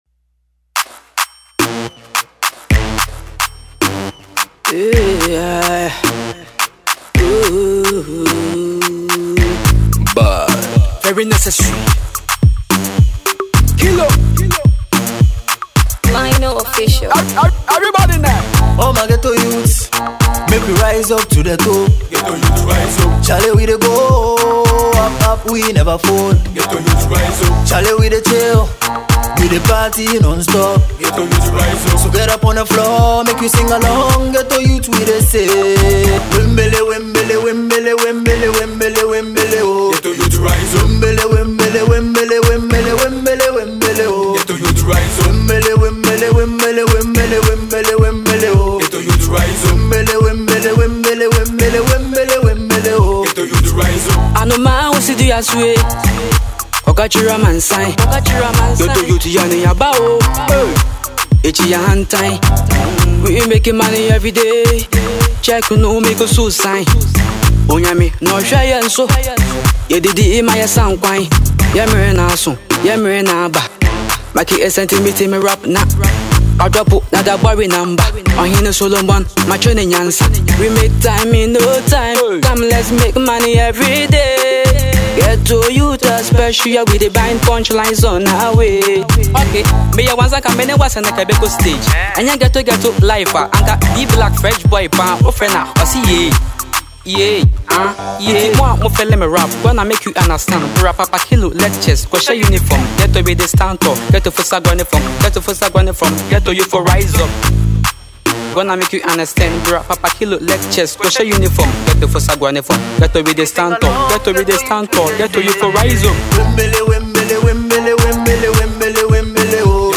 rapper
Its a hot banger!!